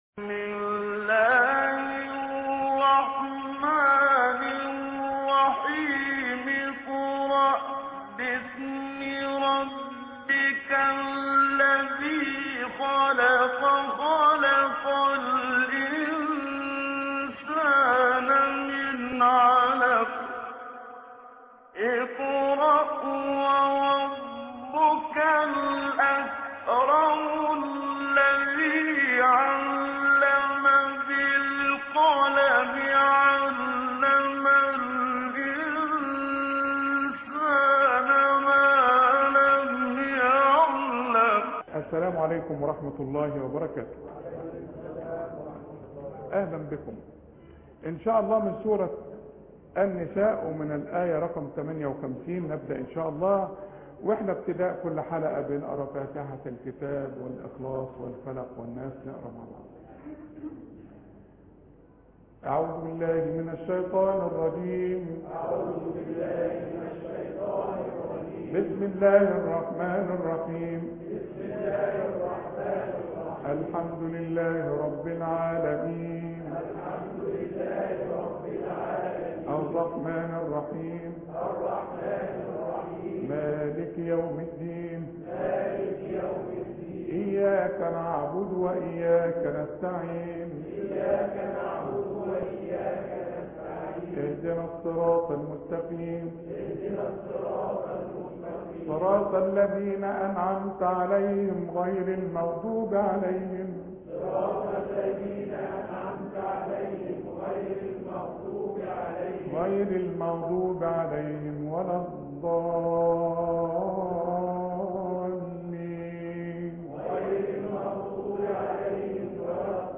قراءة من الاية 58 فى سورة النساء